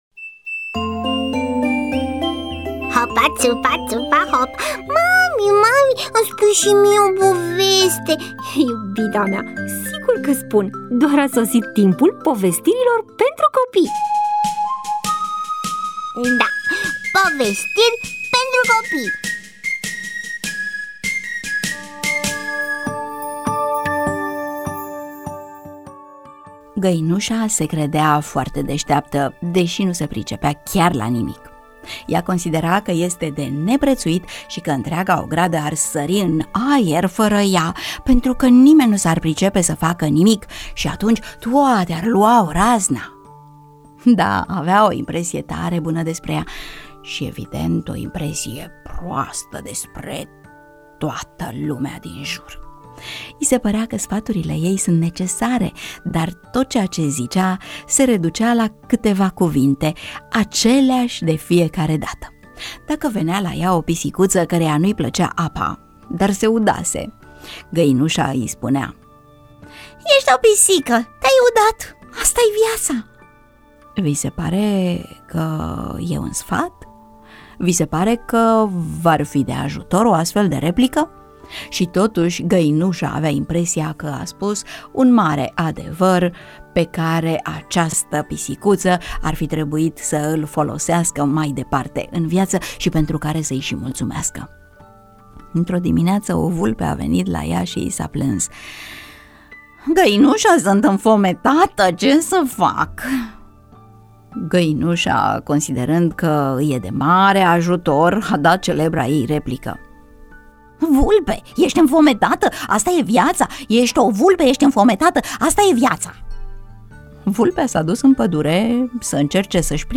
EMISIUNEA: Povestiri pentru copii